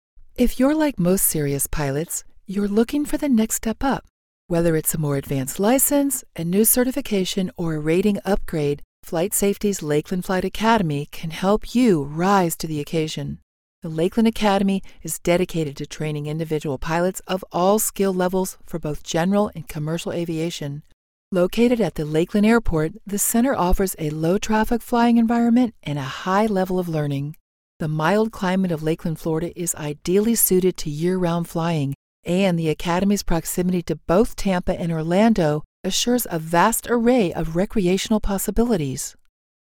Sprechprobe: Industrie (Muttersprache):
An unorthodox voice artist/actor with a smoky, melodic voice, chocked-full of flexibility and instinctively versatile for portraying female + male meanies, arrogant snobs, charming - scathing villains and especially has a hearty repertoire of ordinary (and insane) characters (baby to senior) & accents from all-around.